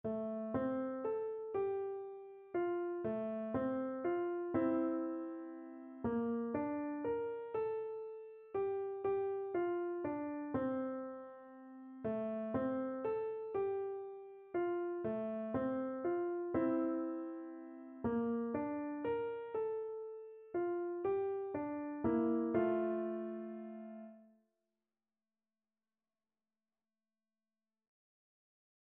Free Sheet music for Piano
Gently = c.120
3/4 (View more 3/4 Music)
F major (Sounding Pitch) (View more F major Music for Piano )
Piano  (View more Beginners Piano Music)
Traditional (View more Traditional Piano Music)
rock-a-bye_babyPNO.mp3